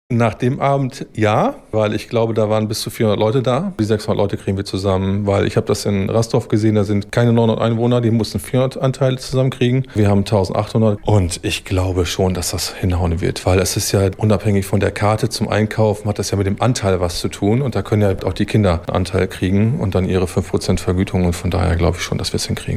Eggermühlens Bürgermeister Markus Frerker ist sich nach der Bürgerversammlung in der vergangenen Woche relativ sicher, dass die benötigten Anteilseigner bis zum Ende der Frist am 13. Mai 2026 zusammenkommen:
O-Ton-3-Frerker.mp3